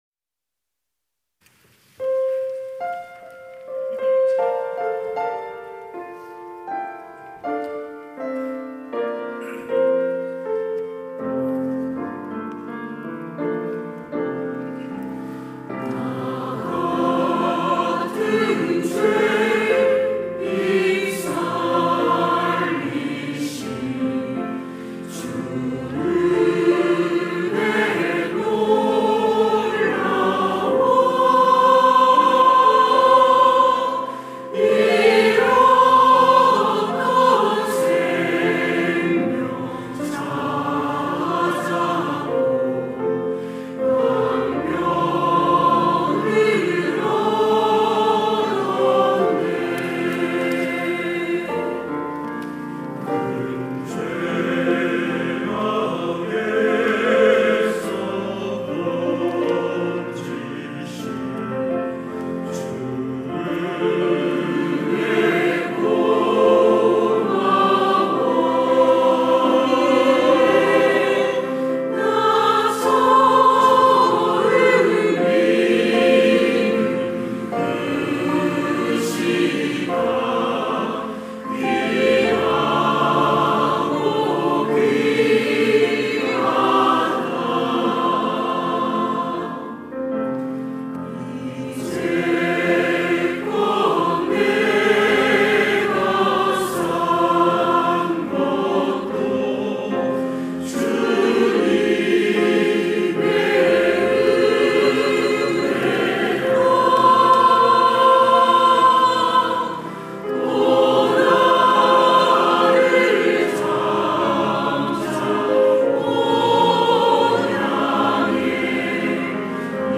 찬양대